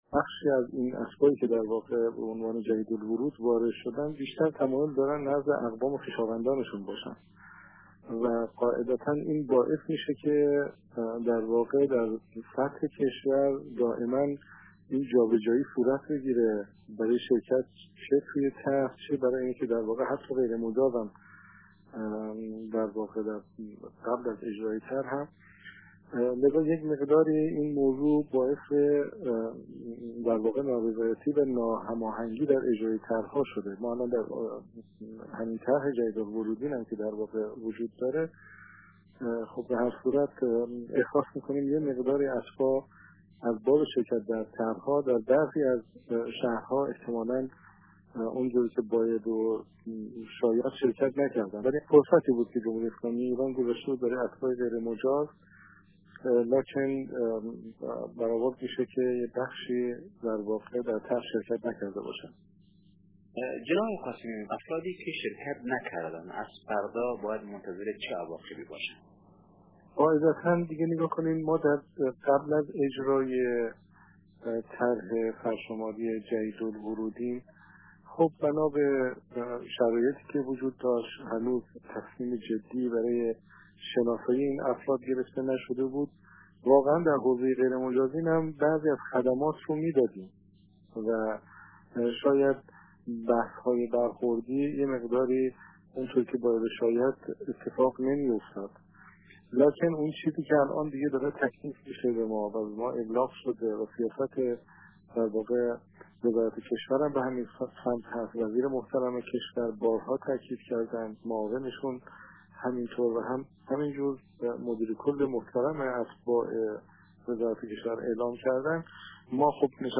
سیدعلی قاسمی مدیر کل امور اتباع و مهاجران خارجی استان خراسان رضوی ایران در مصاحبه با رادیو دری گفت طرح سرشماری، فرصتی بود که وزارت کشور ایران در اختیار اتباع تازه‌وارد و غیرمجاز حاضر در ایران گذاشت، با این حال برخی از این اتباع به دلایلی چون زندگی با اقوام و خویشان و جابه‌جا شدن در شهرهای مختلف ایران، در این طرح شرکت نکردند.